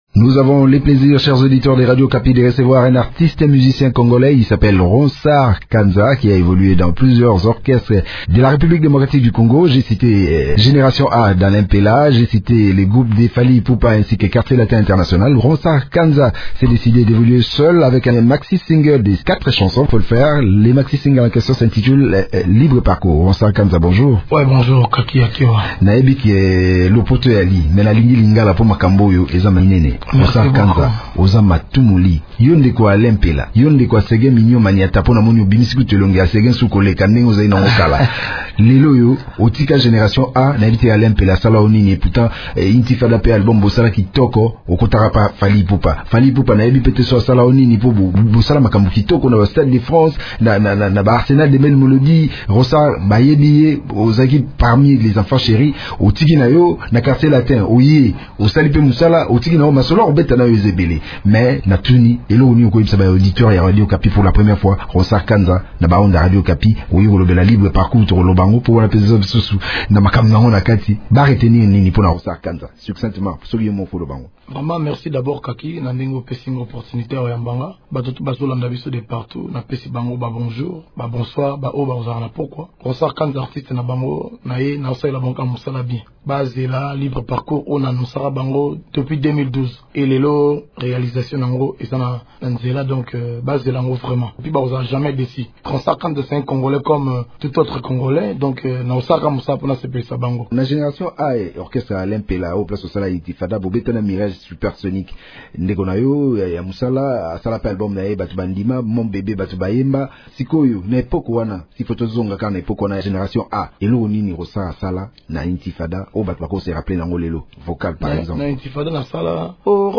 Il a annoncé la sortie de ce maxi-single dans un entretien accordé, il y a quelques jours à Radio Okapi.